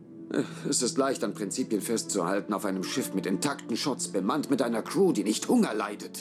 Joachim Tennstedt sagt als Rudolph Ransom den Satz Es ist leicht an Prinzipien festzuhalten, auf einem Schiff mit intakten Schotts, bemannt mit einer Crew, die nicht Hunger leidet. (VOY: Equinox, Teil I)